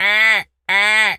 seagul_squawk_deep_03.wav